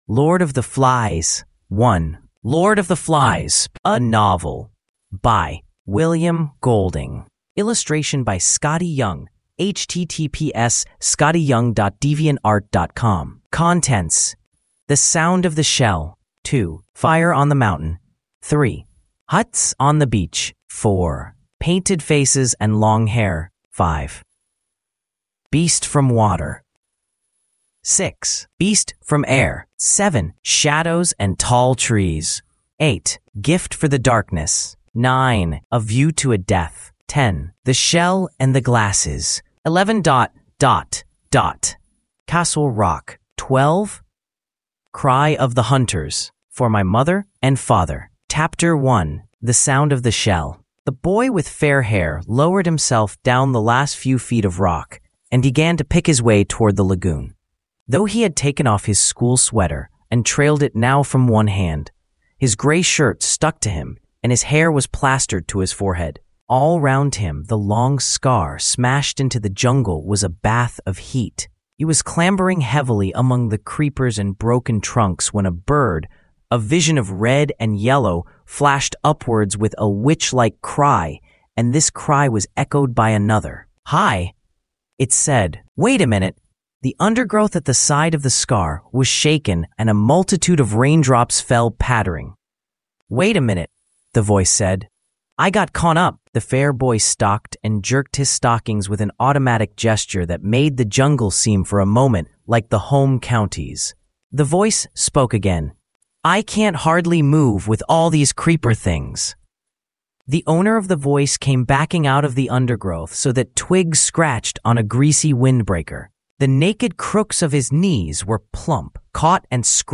Demo (OpenAI)
Implemented lesson audio generation with AI TTS.